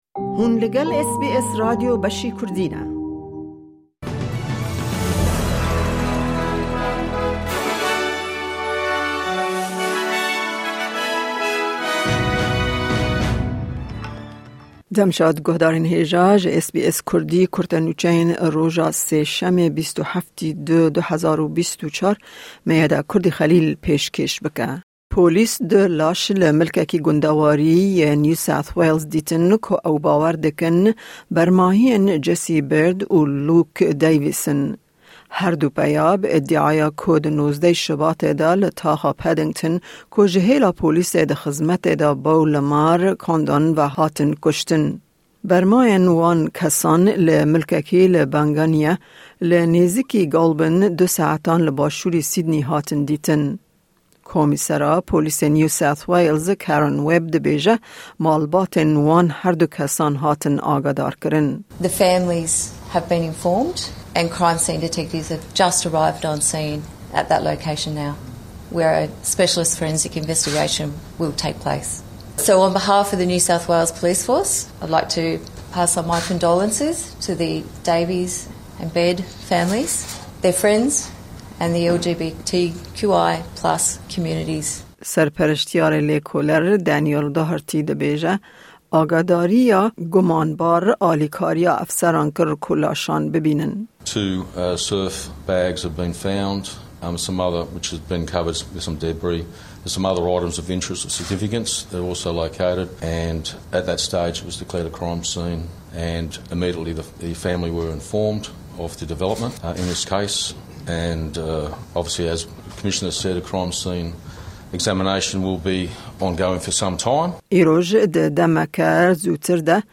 Kurte Nûçeyên roja Sêşemê 27î Şubata 2024